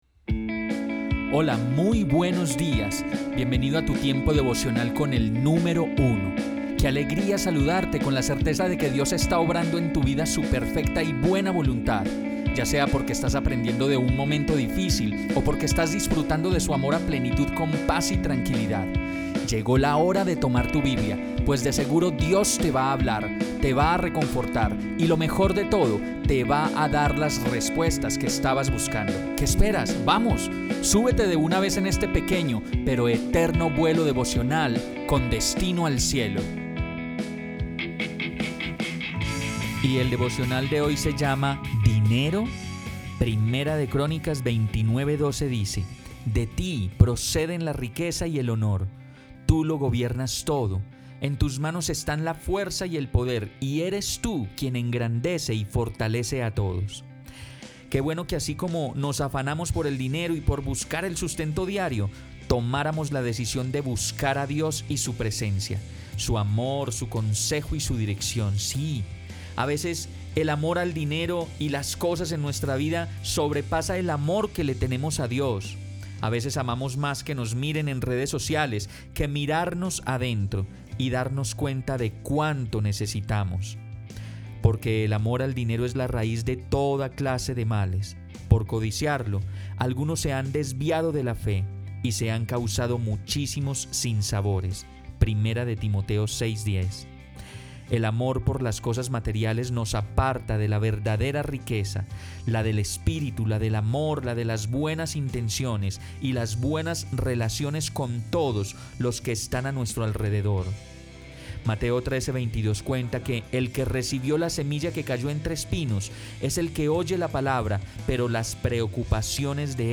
Devocional. Tu Tiempo con el Número Uno…¿Dinero?. 19 Julio